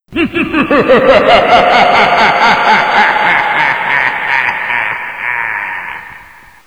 laught.wav